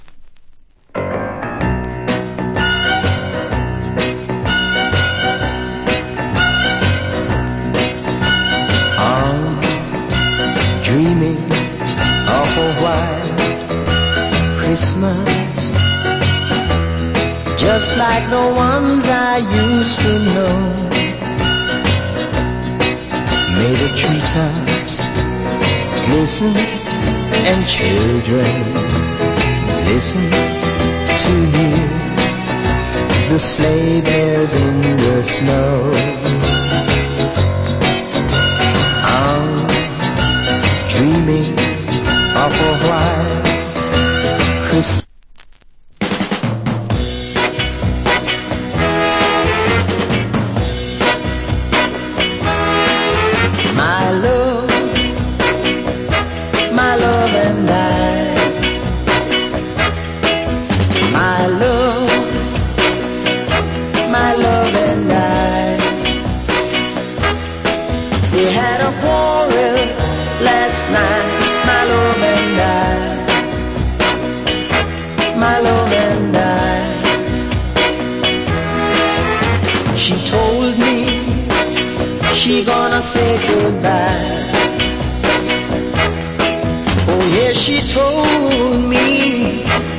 Nice Christmas Song.